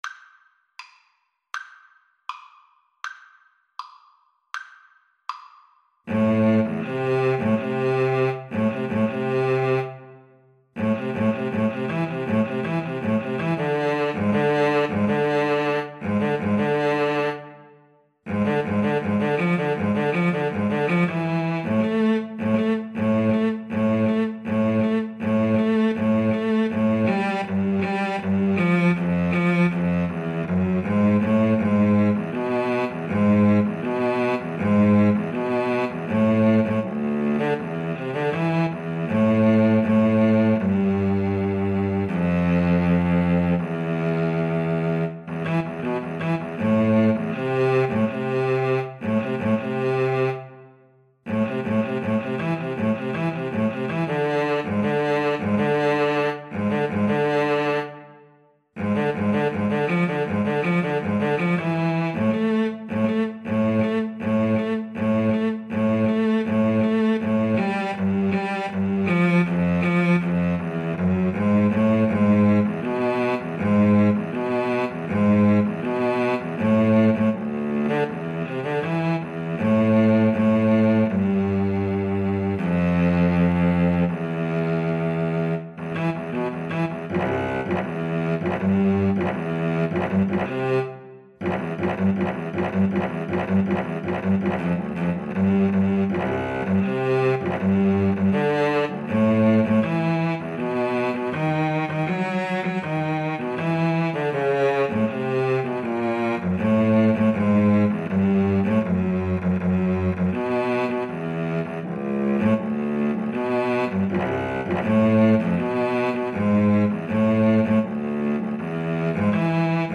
Free Sheet music for Cello Duet
Cello 1Cello 2
A minor (Sounding Pitch) (View more A minor Music for Cello Duet )
Allegretto =80
2/4 (View more 2/4 Music)
Classical (View more Classical Cello Duet Music)